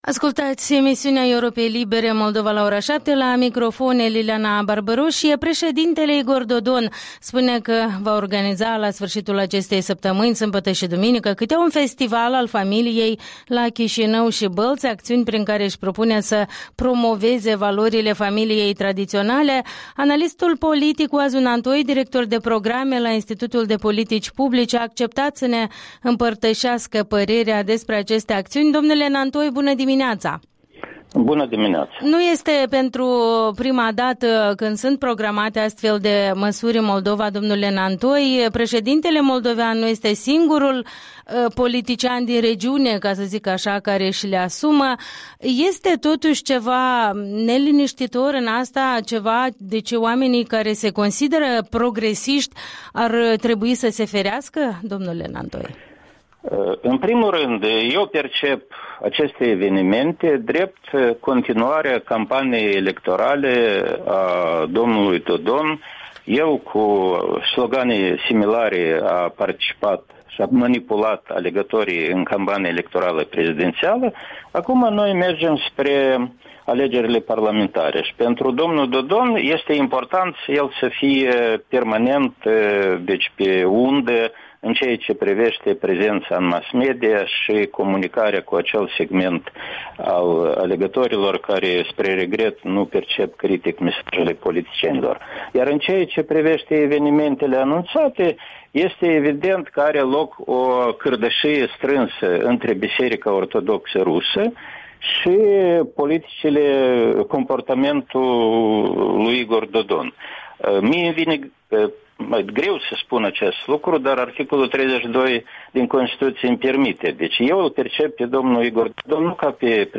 Interviul dimineții: cu Oazu Nantoi (IPP)